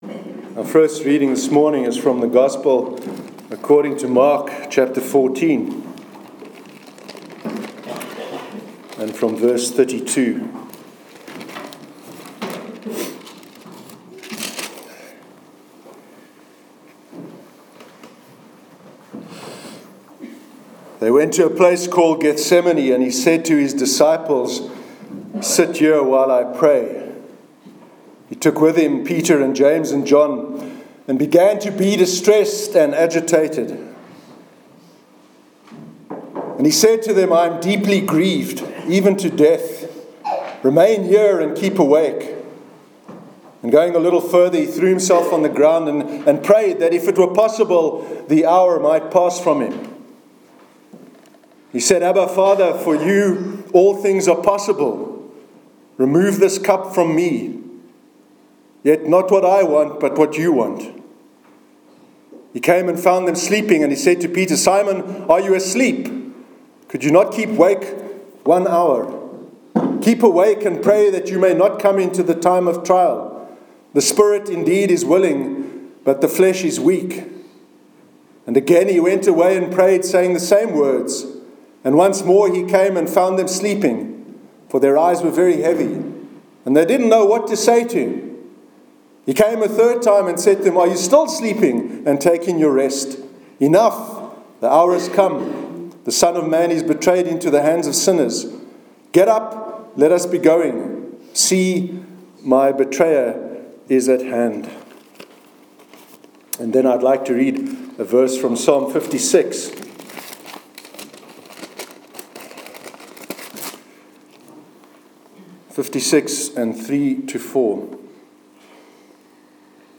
Sermon on Courage- 7th January 2018
sermon_7th_january_2018.mp3